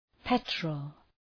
Προφορά
{‘petrəl}